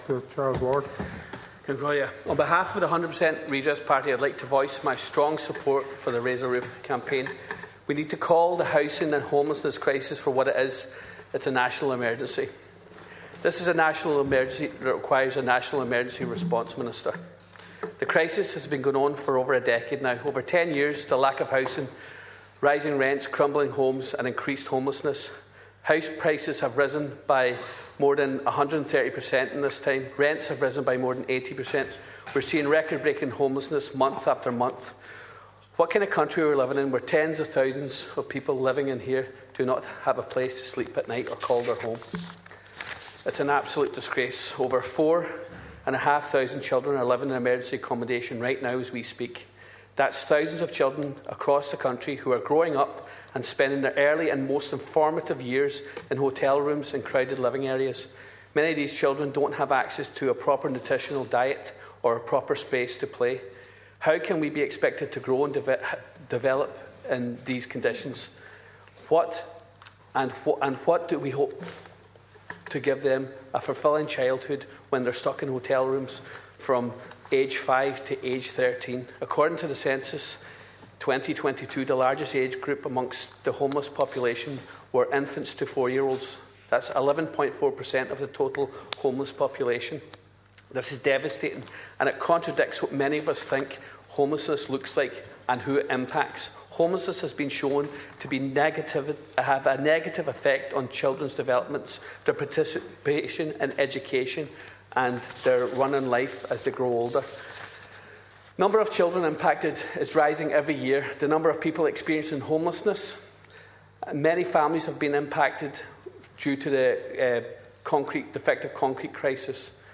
Speaking in the Dáil last week, Deputy Charles Ward urged the Minister for Housing, James Browne, to call the housing and homelessness crisis what it is.